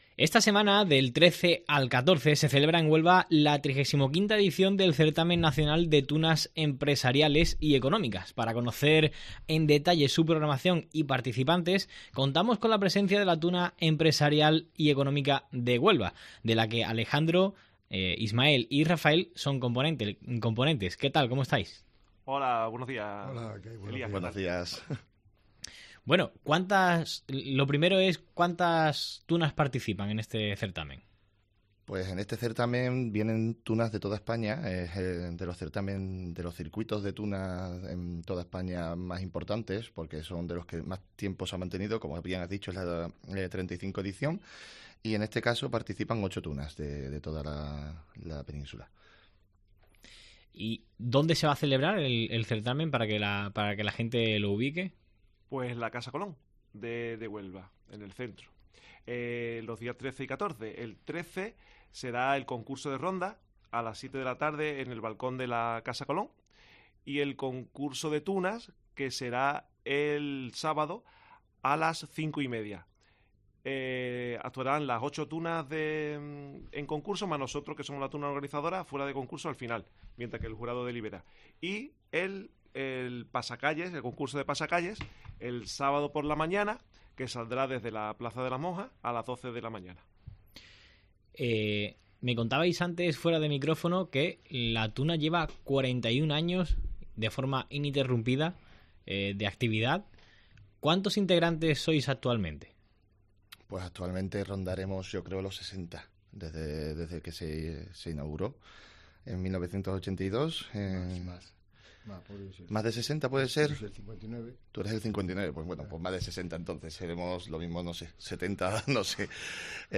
Entrevista a la Tuna de empresariales de Huelva